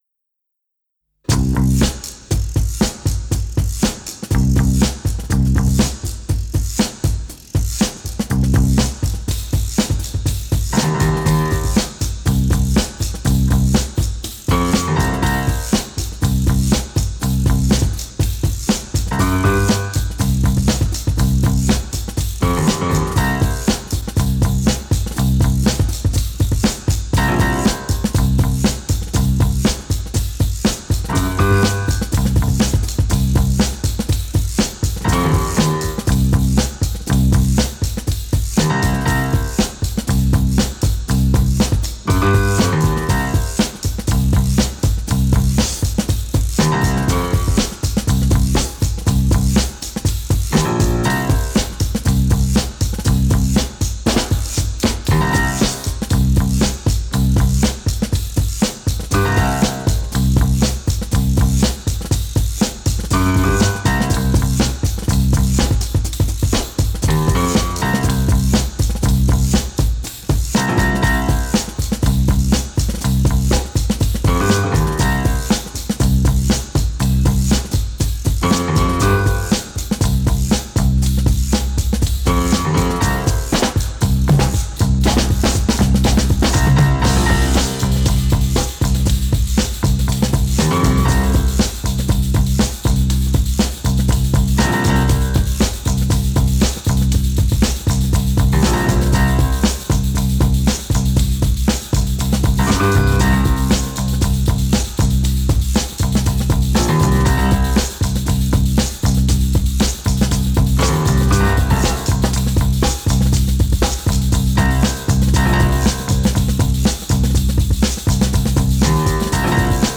one will find another rhythmic banger